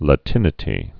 (lə-tĭnĭ-tē)